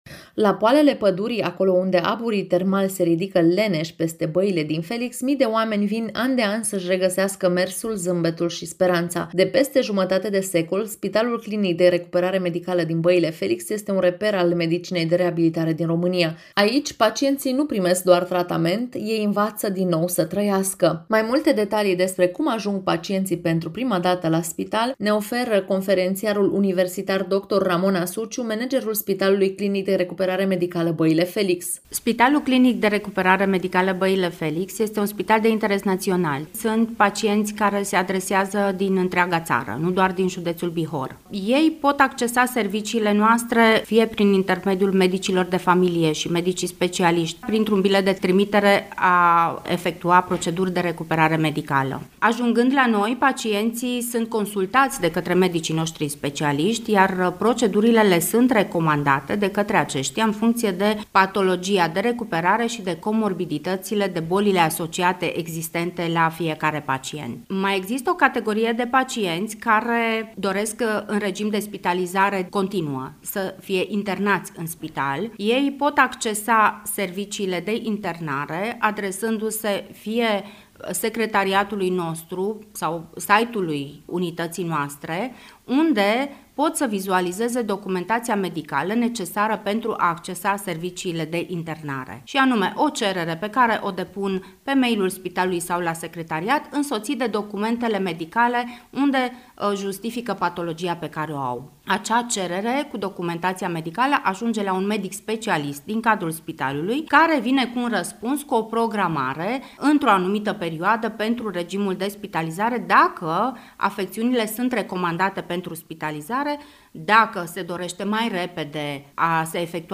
reportaj-spital-recuperare-Baile-Felix.mp3